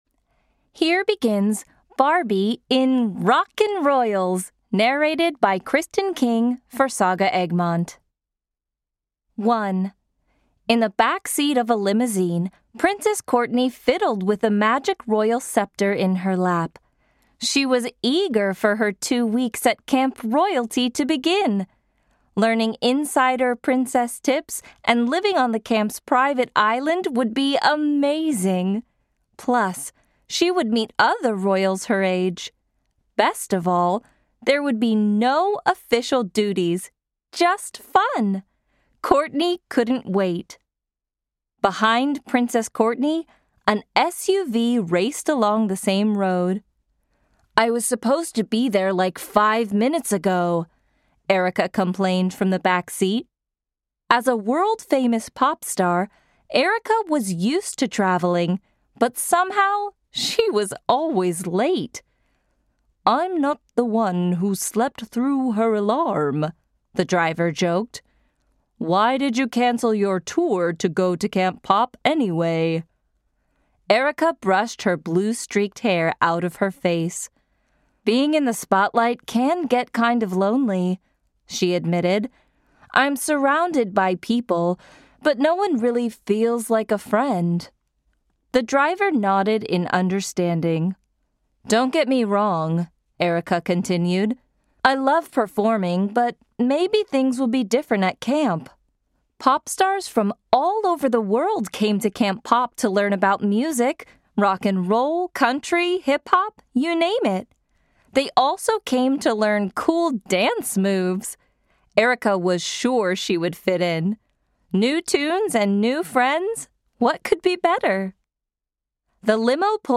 Audio knihaBarbie - Rock N Royals (EN)
Ukázka z knihy